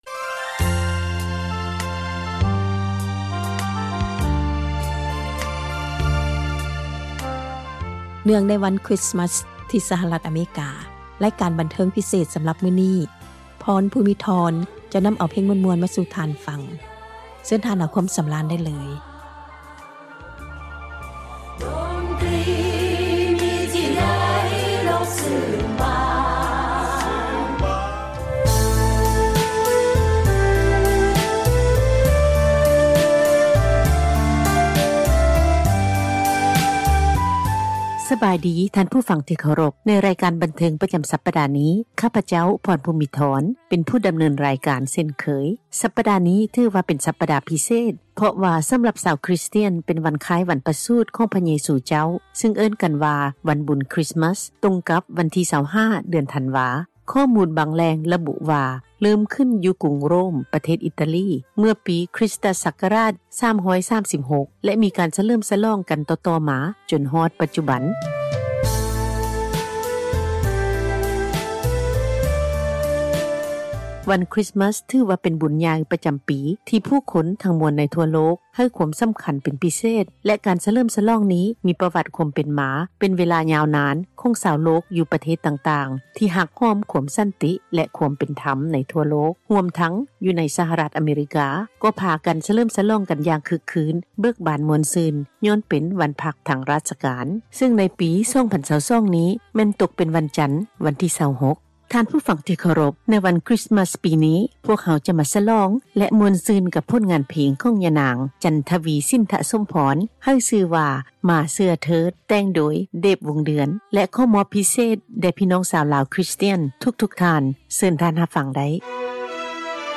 ພ້ອມກັບ ນໍາເພງຄຣິສທ໌ມາສ ມ່ວນໆ ມາເປີດໃຫ້ທ່ານ ໄດ້ຮັບຟັງກັນ